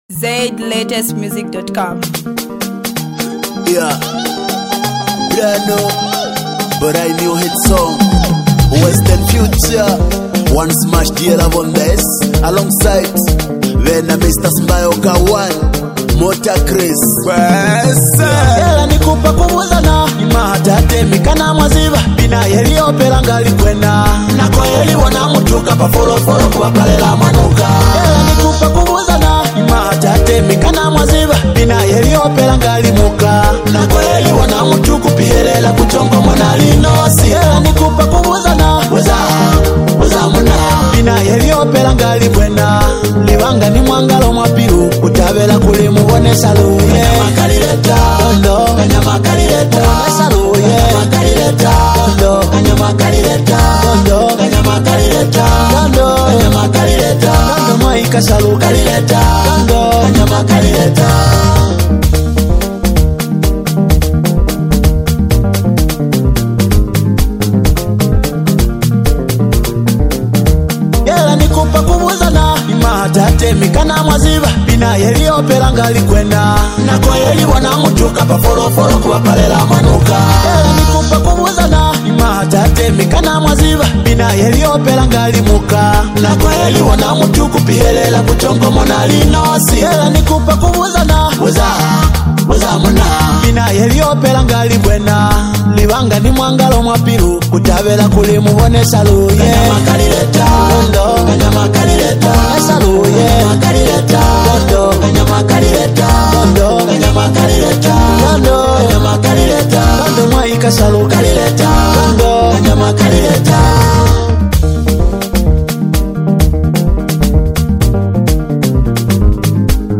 Mbunga singer
adds extra flavor with a vibrant and expressive performance